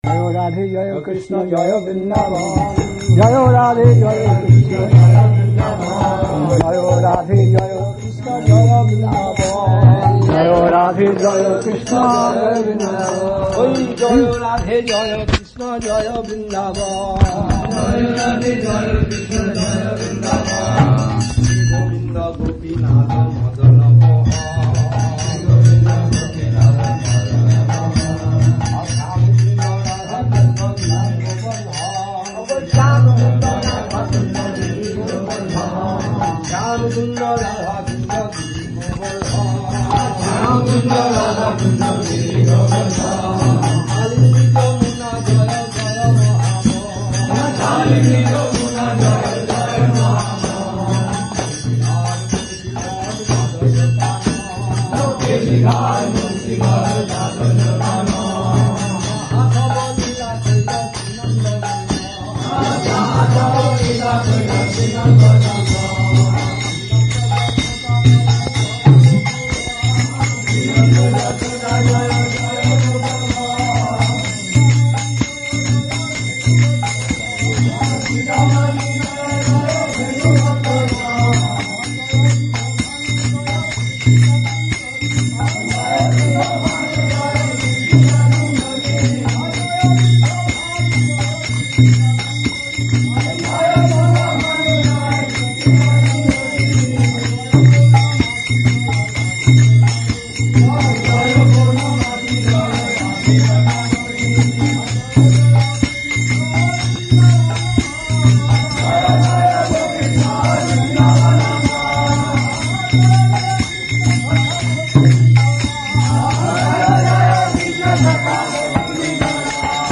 We apologise for the audio quality. It's being posted as vintage classic recordings.
Duration: 00:05:00 Size: 6.87Mb Place: SCSMath Nabadwip Downloaded: 2904 Played: 6793
Tags: Kirttan